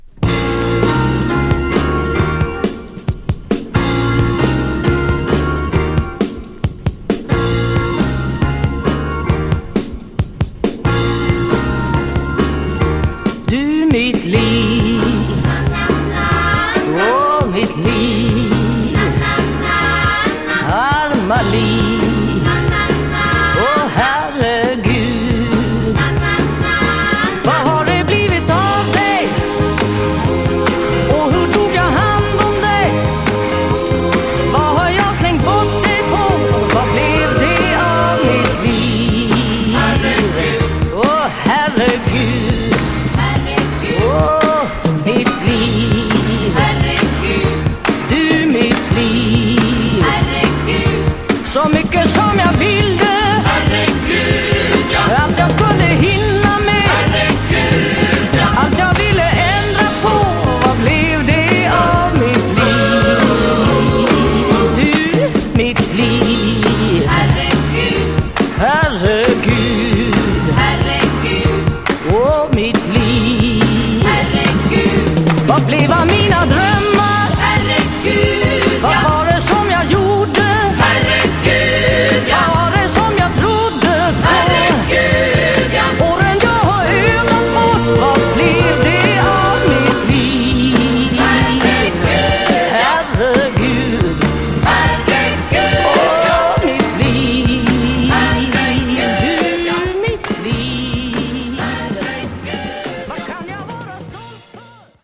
Jazz Vocal sweden